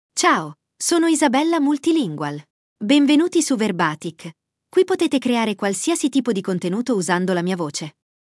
Isabella MultilingualFemale Italian AI voice
Isabella Multilingual is a female AI voice for Italian (Italy).
Voice sample
Listen to Isabella Multilingual's female Italian voice.
Isabella Multilingual delivers clear pronunciation with authentic Italy Italian intonation, making your content sound professionally produced.